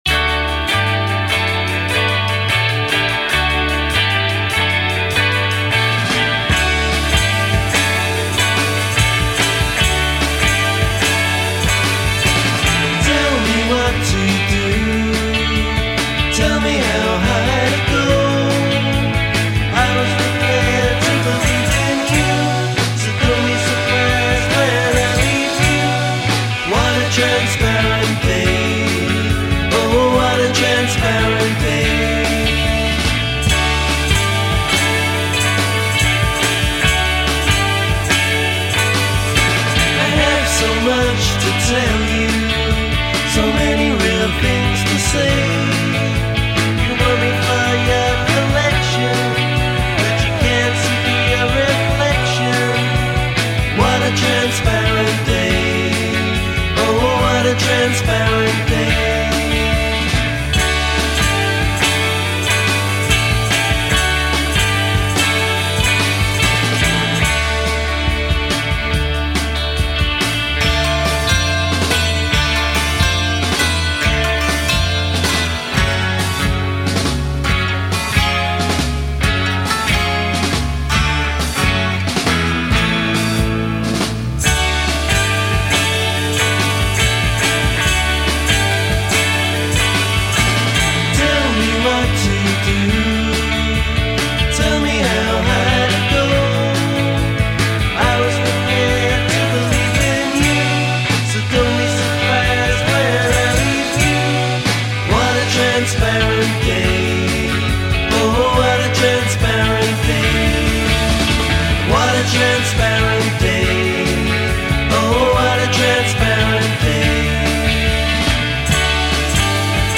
The band play homage to 1960’s West Coast Psychedelia